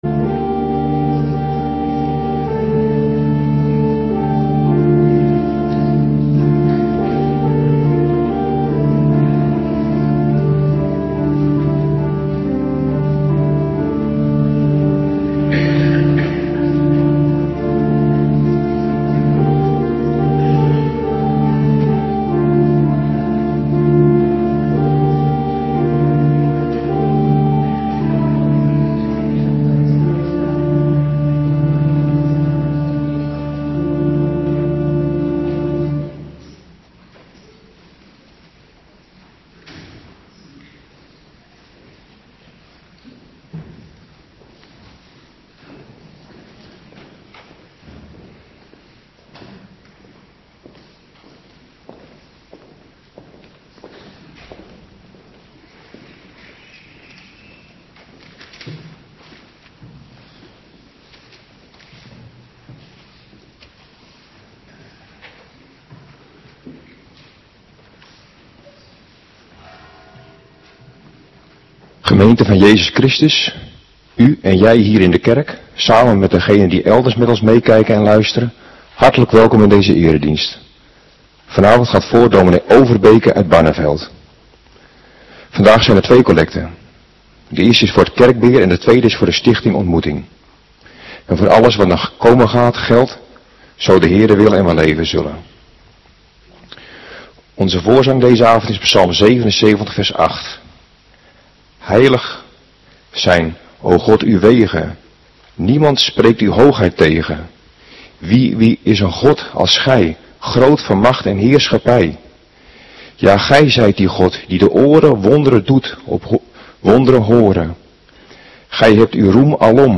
Avonddienst 19 april 2026